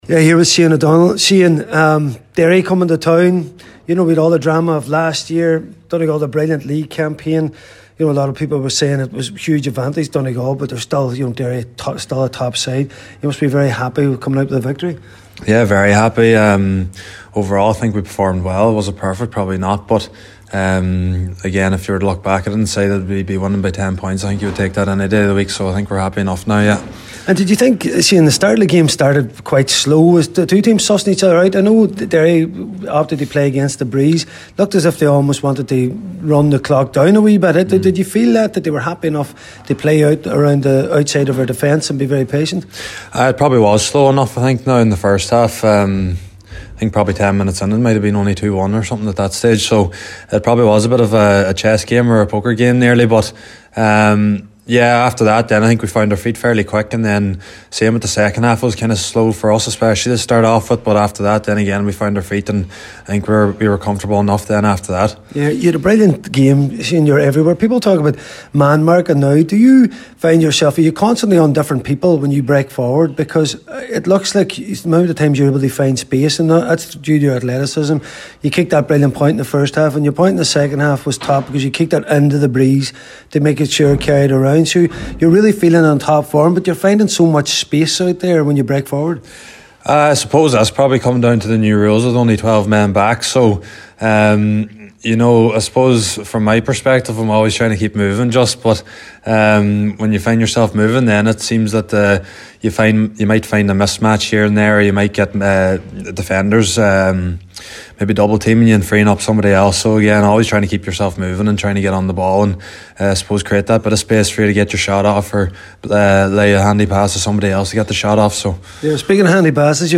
after today’s game